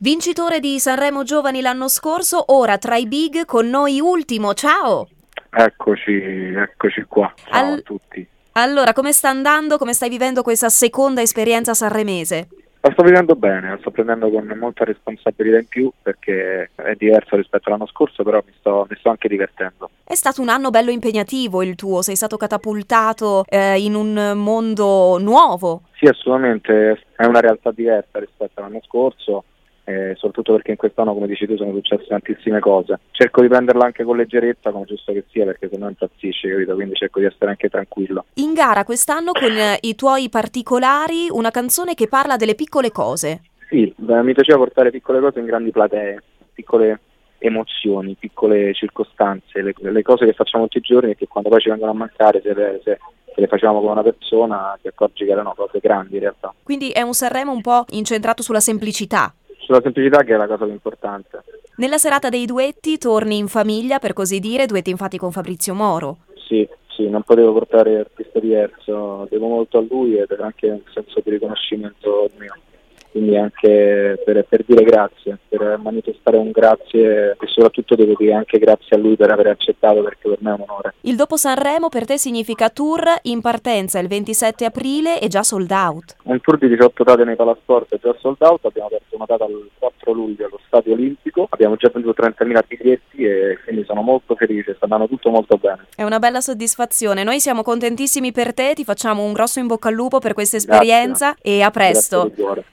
SANREMO 2019: LA NOSTRA INTERVISTA A ULTIMO